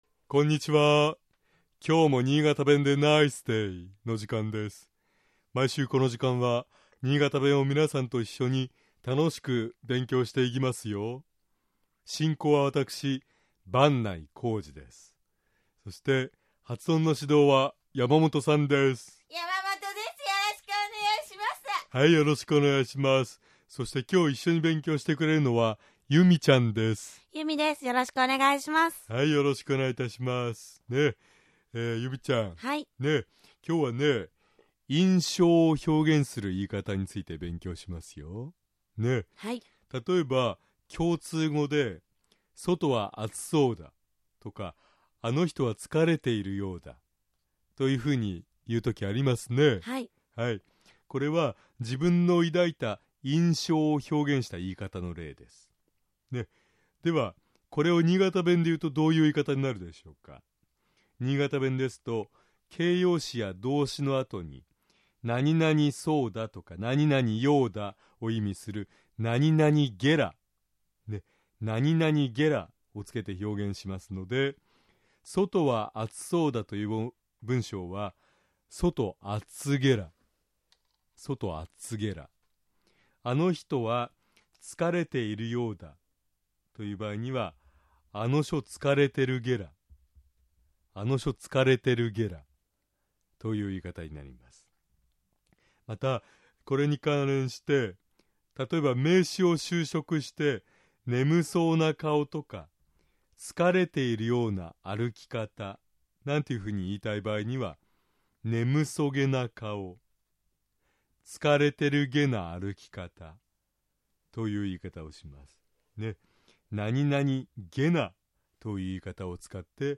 尚、このコーナーで紹介している言葉は、 主に新潟市とその周辺で使われている方言ですが、 それでも、世代や地域によって、 使い方、解釈、発音、アクセントなどに 微妙な違いがある事を御了承下さい。